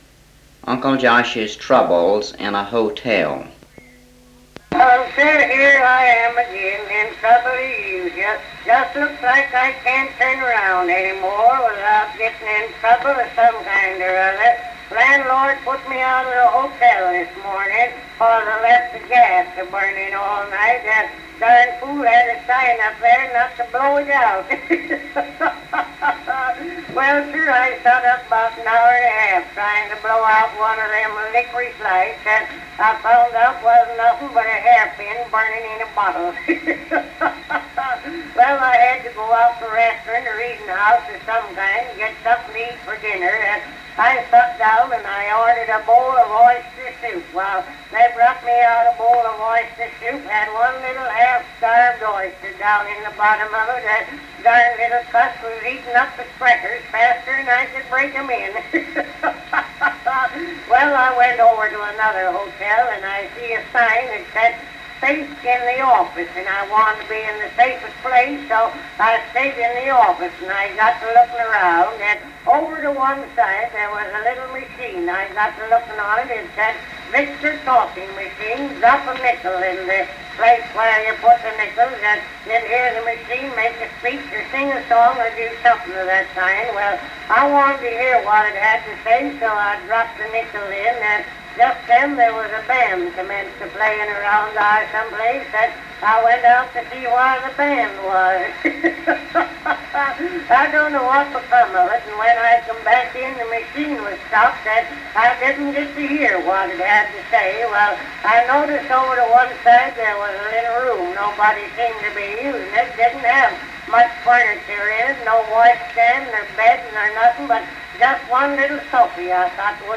Cal Stewart's comedy routine, Uncle Josh's troubles in a hotel.